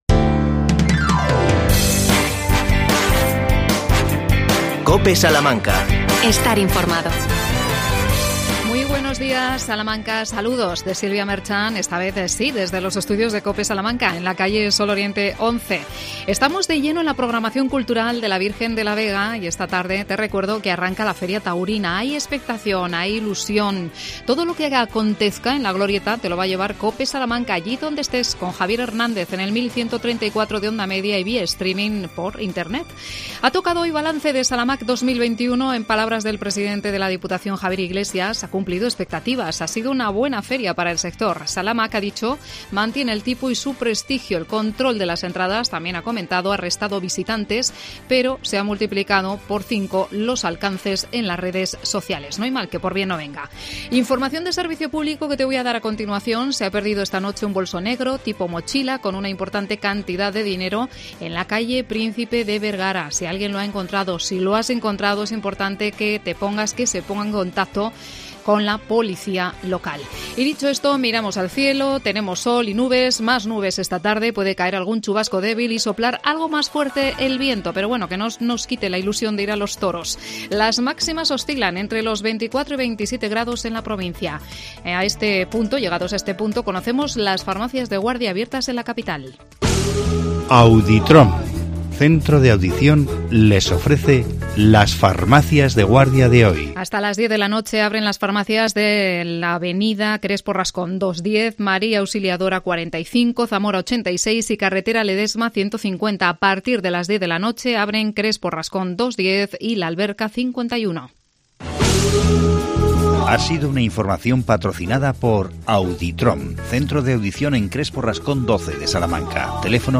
AUDIO: Toda la información del programa Salamanca a Tope. Entrevistamos a María José Fresnadillo,concejala de Salud Pública.